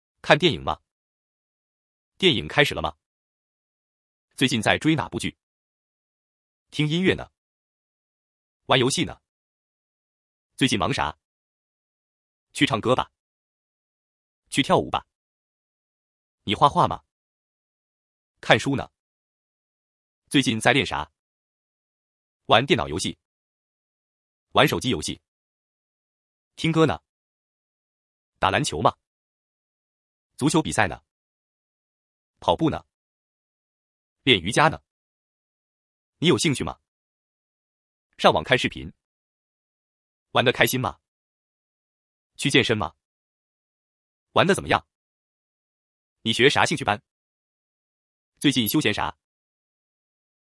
Fast Spoken Mandarin (native speech)